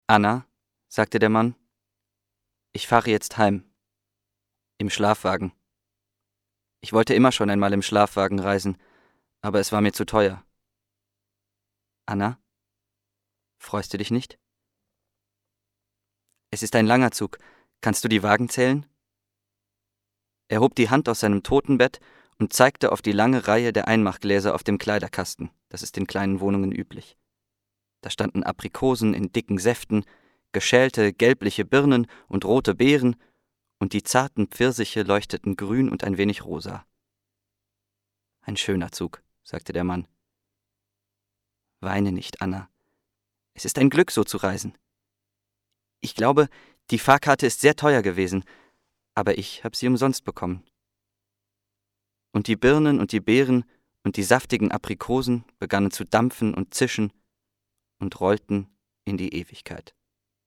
Junge Stimme, derzeit in Schauspielausbildung, für Werbung, Hörspiele und Hörbücher
Kein Dialekt
Sprechprobe: Sonstiges (Muttersprache):